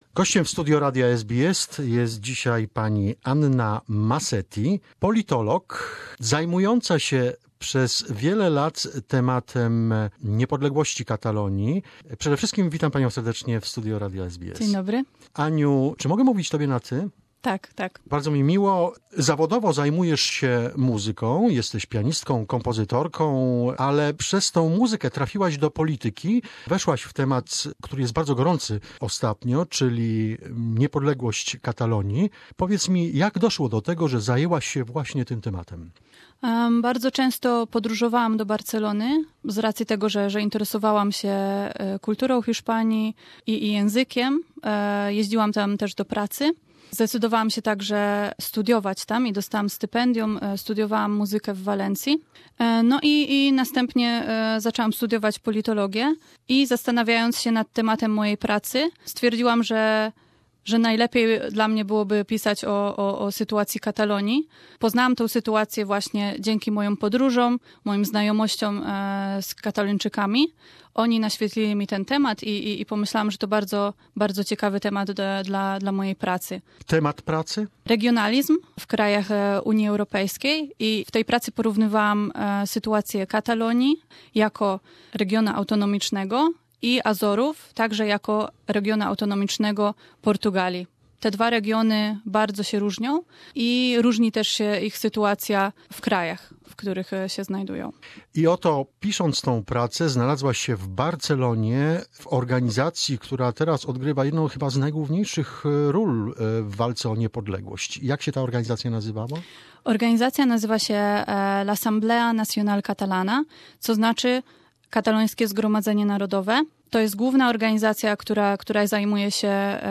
talks about Catalonia and peaceful struggle for independence....This is part one of the interview.